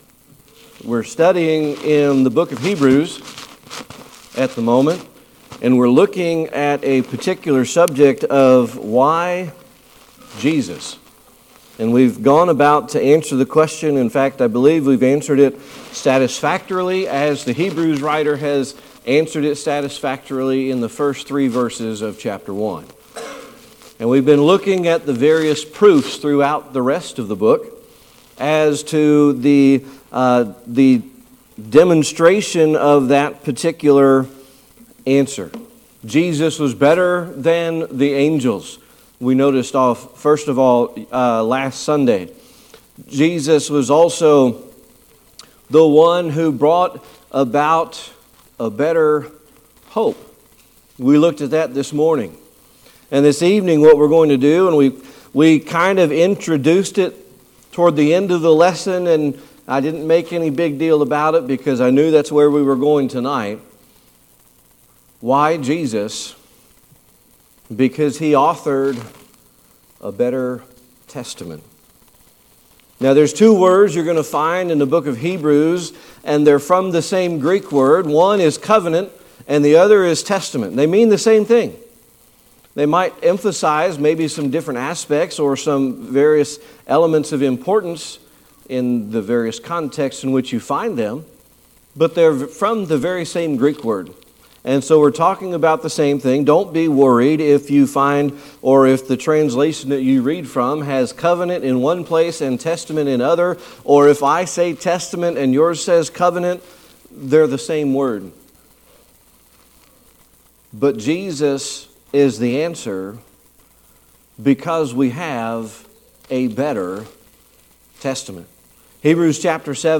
Service Type: Sunday Evening Worship